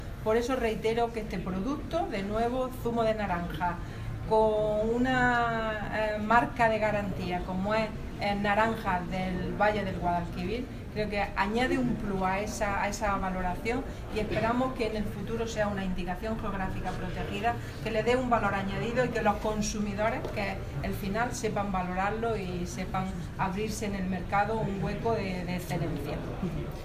Declaraciones de Carmen Ortiz sobre zumo amparado por la marca de calidad 'Naranjas del Valle del Guadalquivir' 2